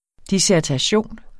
Udtale [ disæɐ̯taˈɕoˀn ]